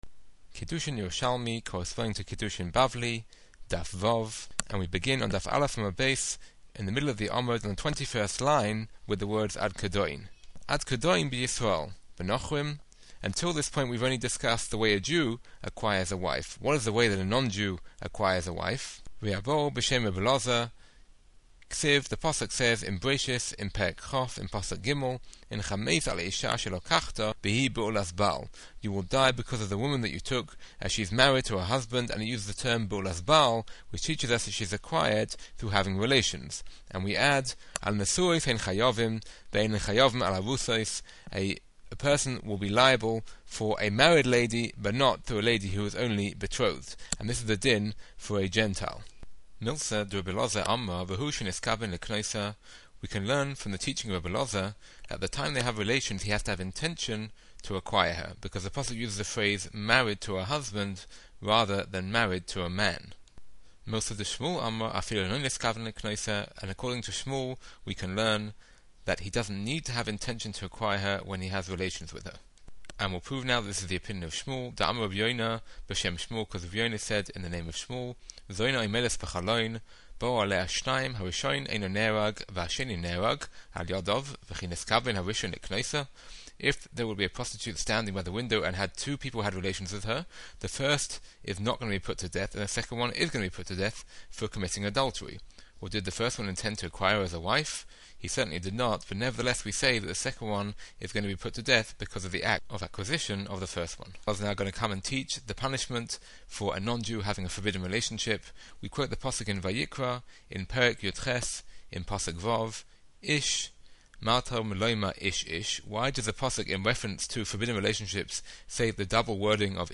MP3's with detailed explanations of every Daf's Yerushalmi Matchup